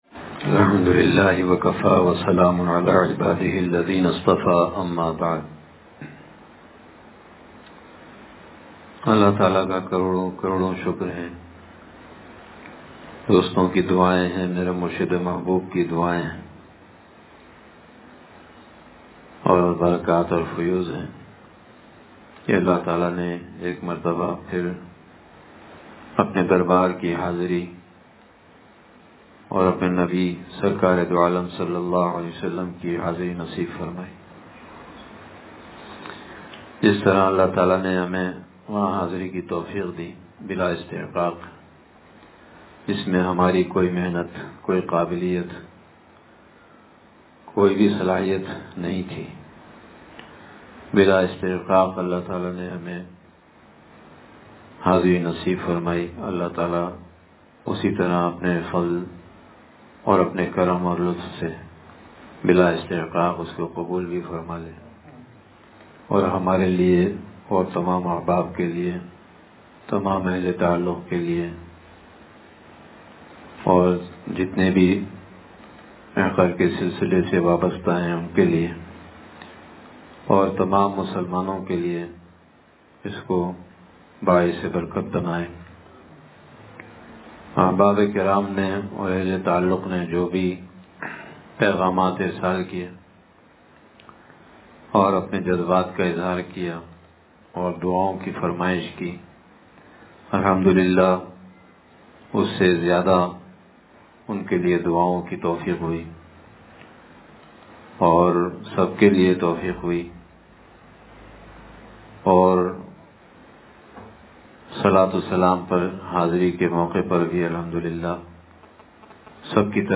حضرتِ والا کی مجلس عمرہ شریف سے واپسی کے بعد